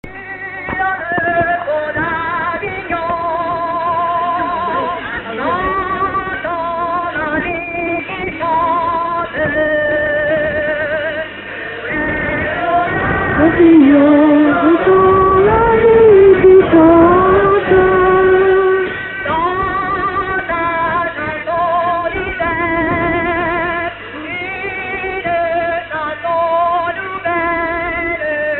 Localisation Île-d'Yeu (L')
circonstance : fiançaille, noce ;
Pièce musicale inédite